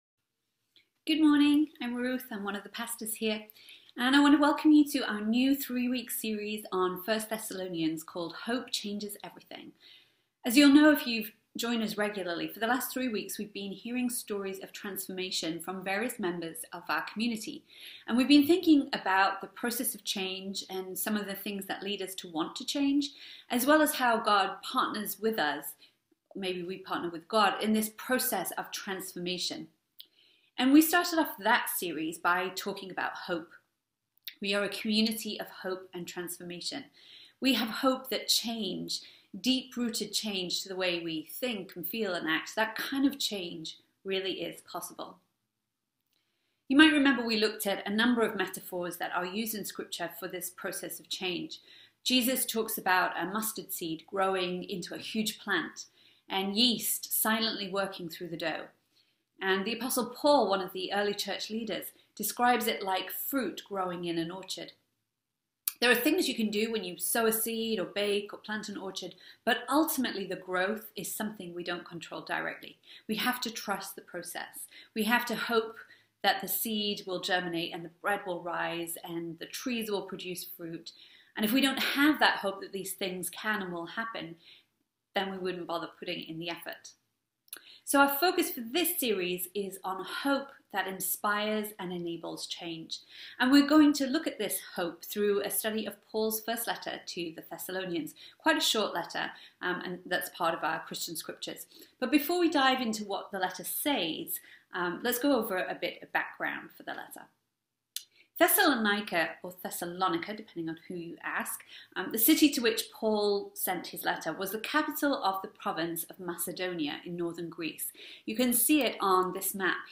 A message from the series "Stories of Faith." In this last week of the series, we explored the story in Luke's Gospel of the woman who washed Jesus' feet.